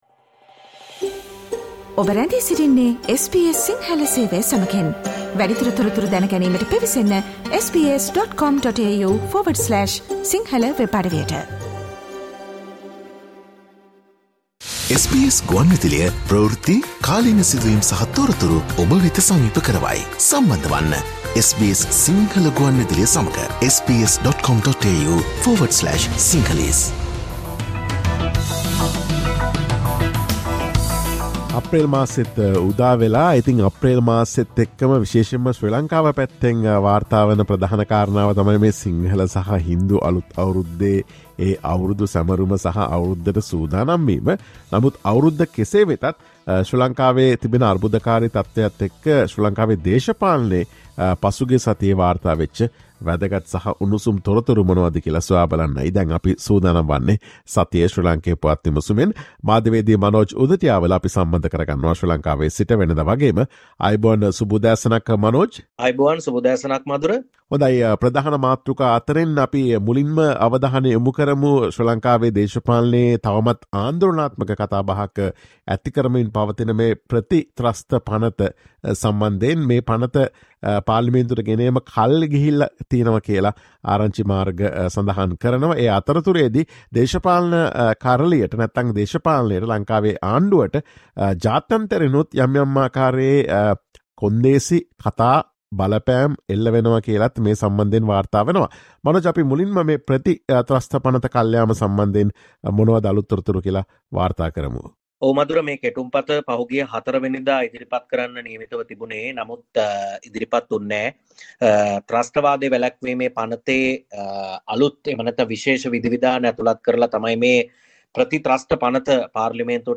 SBS Sinhala radio brings you the most prominent news highlights of Sri Lanka in this featured current affair segment every Monday.